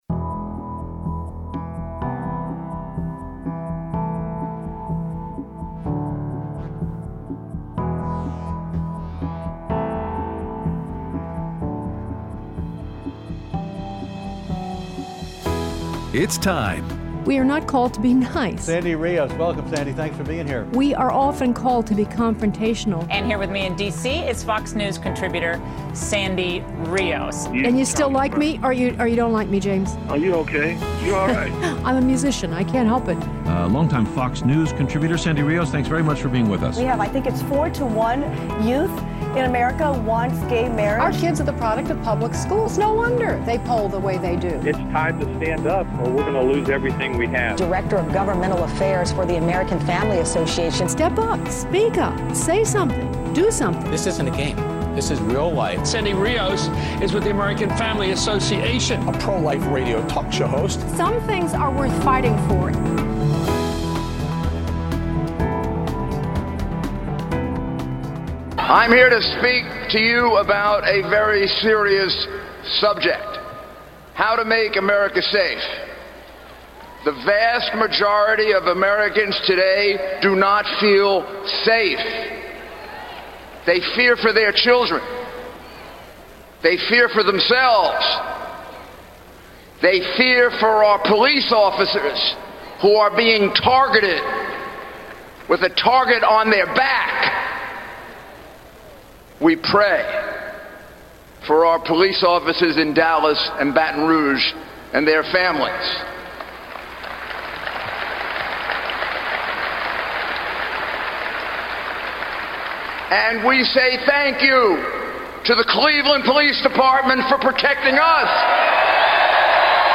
Live at the RNC Day 2